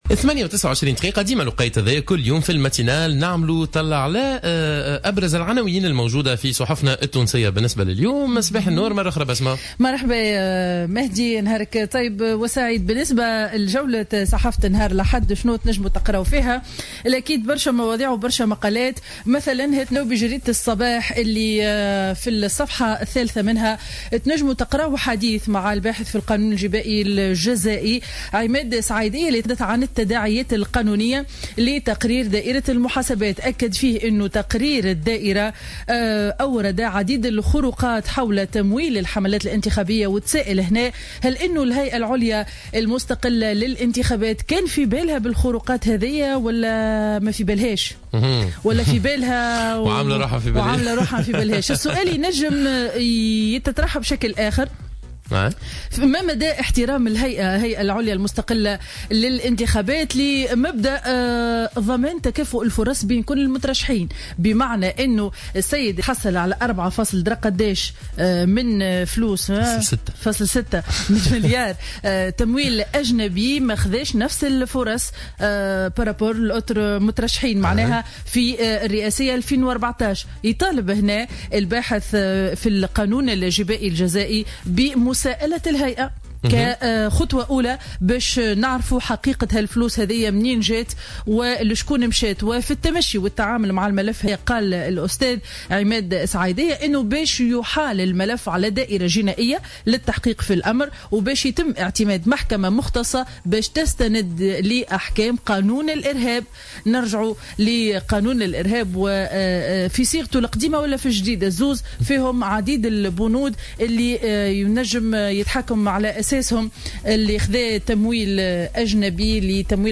Revue de presse du dimanche 23 août 2015